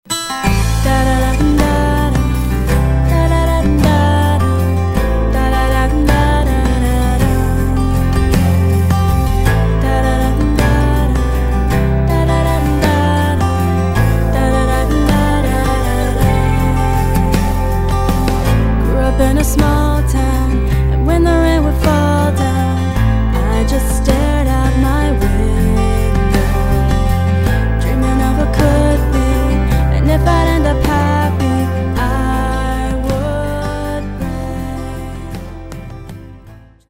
Recueil pour Mélodie/vocal/piano